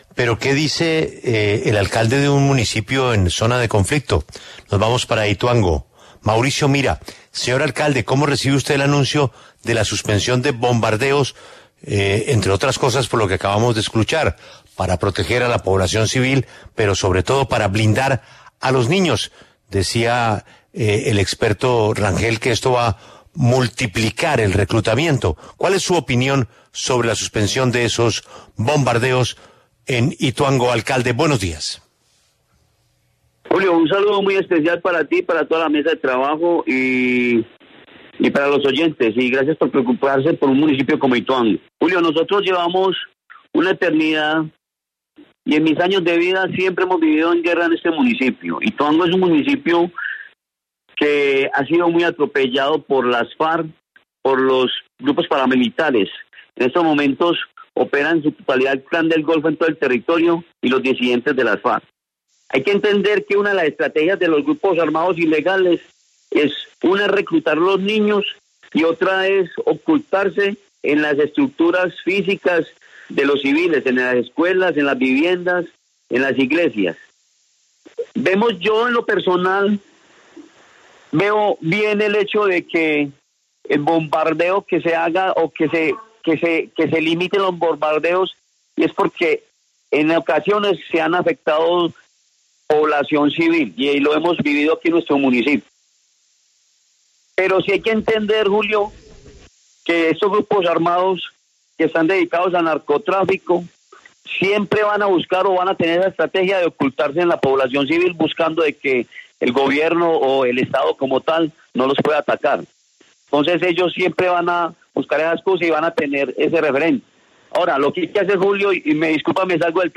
En La W el alcalde de Ituango, Edwin Mauricio Mira, aseguró estar de acuerdo sobre la suspensión de bombardeos en el país para proteger a la población civil de este municipio.